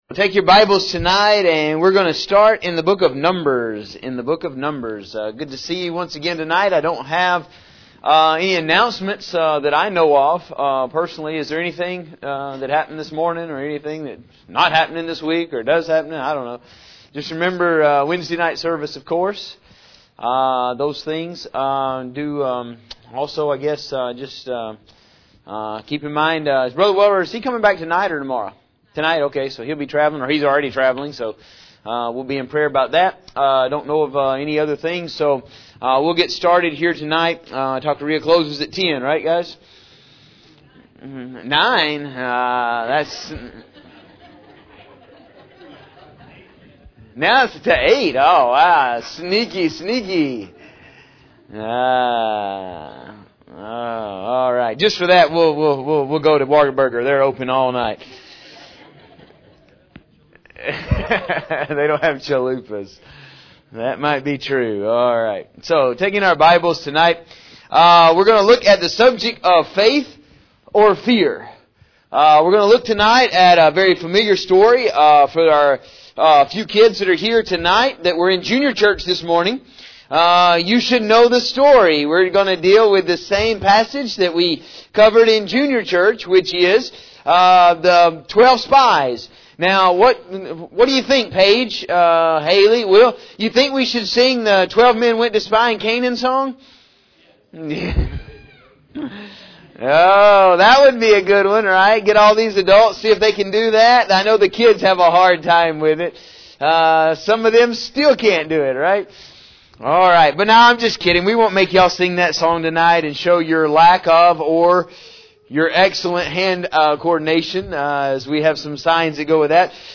In this sermon we are going to look at the 12 spies being sent into the land of Canaan.